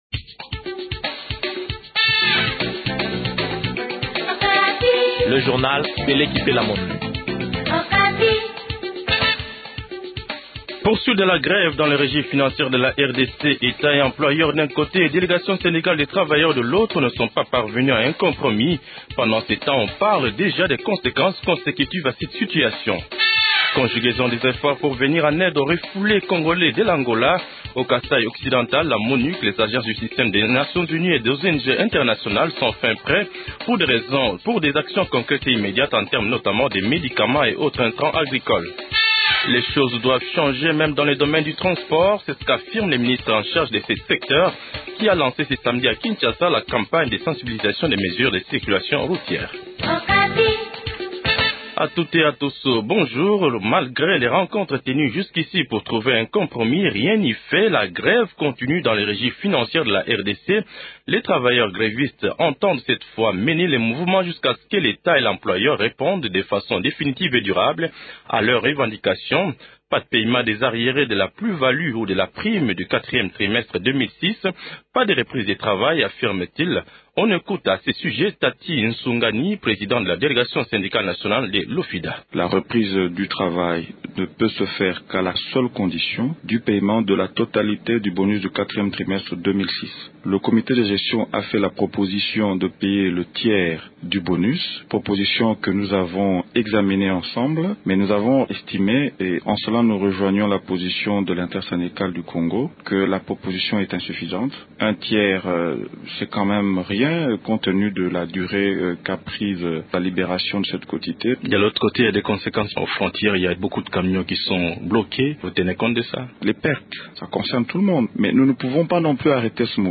Journal Français Matin 08h00